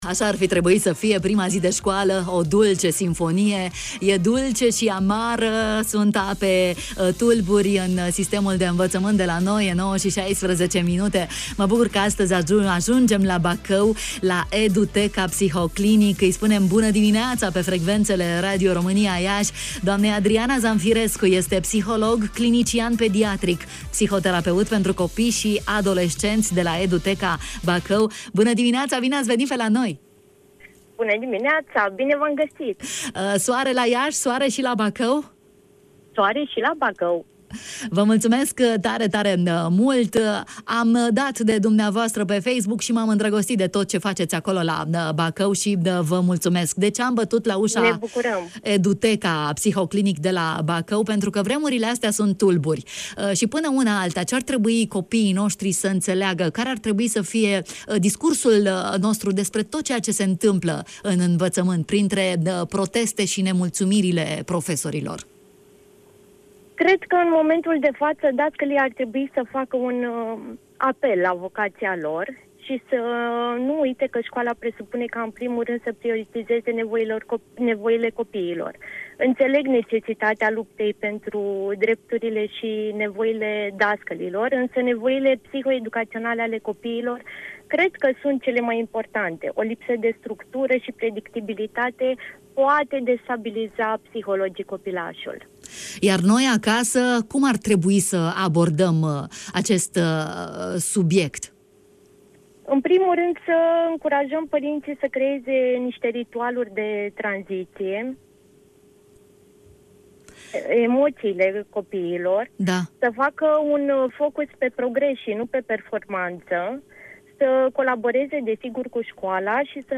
Eduteca Bacău în direct la Radio Iași